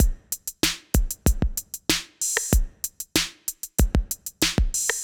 Index of /musicradar/80s-heat-samples/95bpm